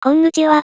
生成音声にノイズが混じる程度なので、まずは試用版で API 呼び出しを固めるのが効率的です。
が無事生成され、Qumcum の声で「コンニチワ。」と再生。
※Demo 版では“に”→“ぬ”と置換される制限あり
konnichiwa.wav